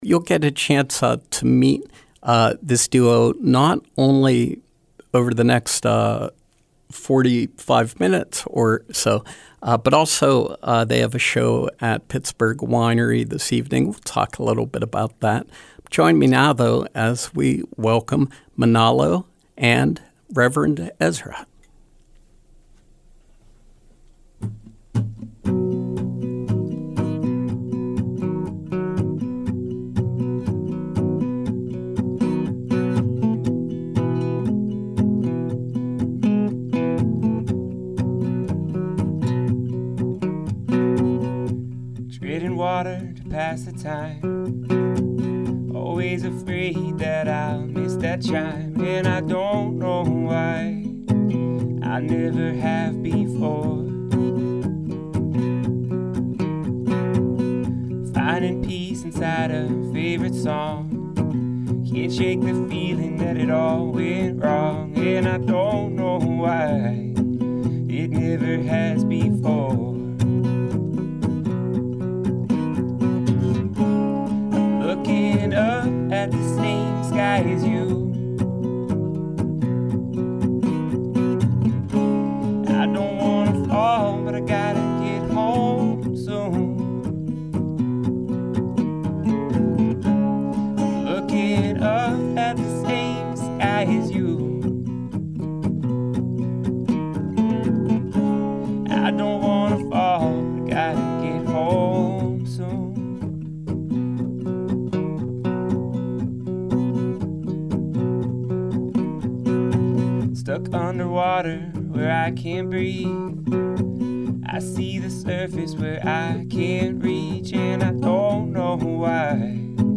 Music and conversation with touring duo